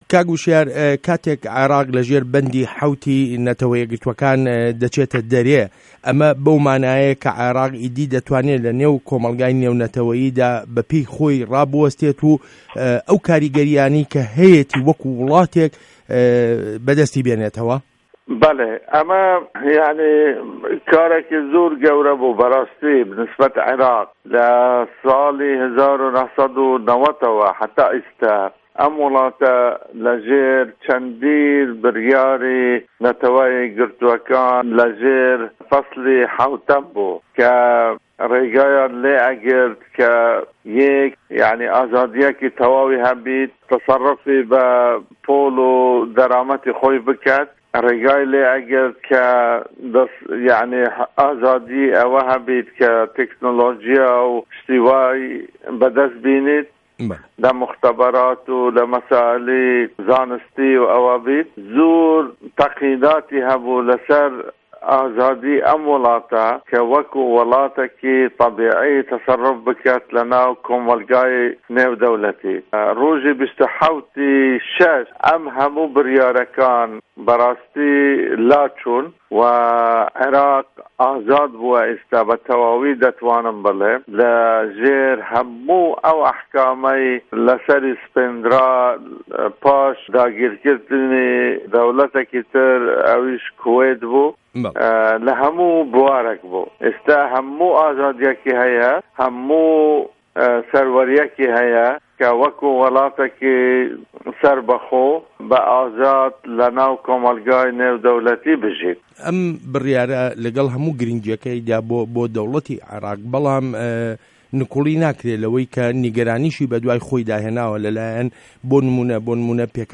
وتووێژ له‌گه‌ڵ هوشیار زێباری